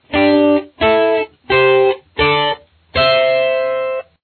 Here’s an easy-to-remember pattern that spans 4 of the 5 pentatonic patterns: